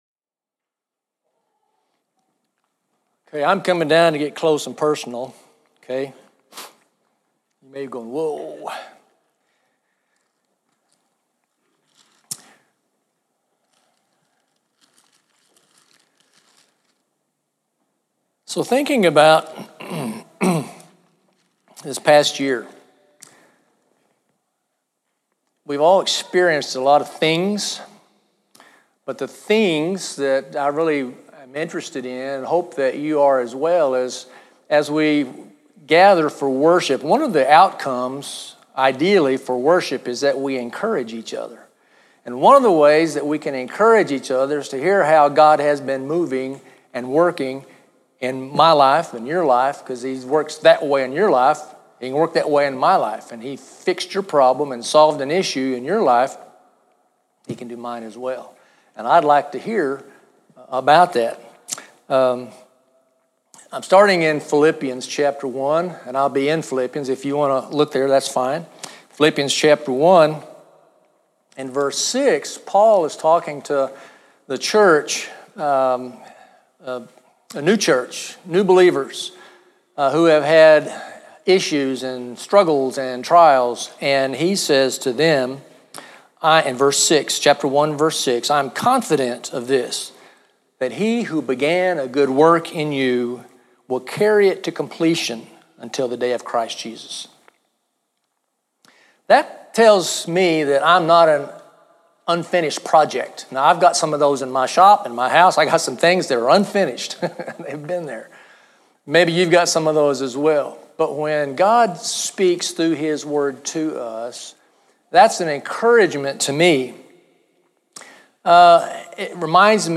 He encouraged us to look at the way God has worked in our lives in the past year and to look to this coming year and how we will join God in His work in and through our lives. [Media Note: Part of the message included testimonies from several in the audience and those portions are not on the recording.]